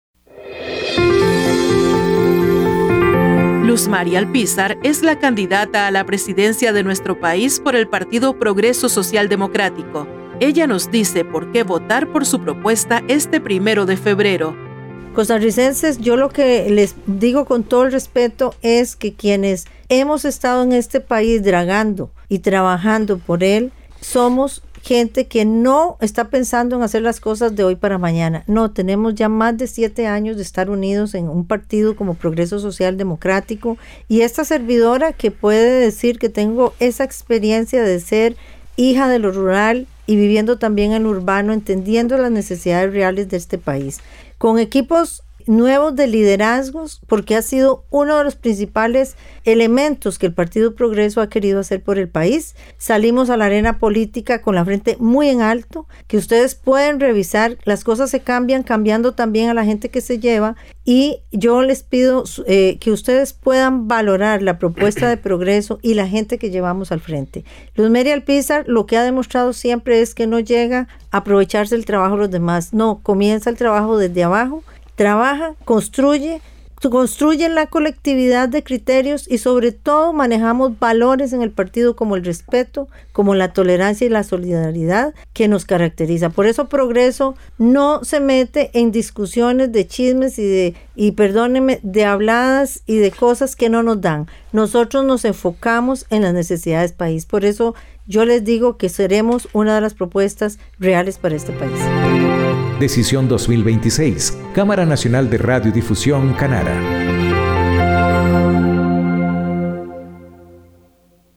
Mensaje de Luz Mary Alpízar Loaiza a los oyentes
Luz Mary Alpízar es la candidata a la presidencia de nuestro país por el Partido Progreso Social Democrático, ella nos dice por qué votar por su propuesta este próximo 1 de febrero.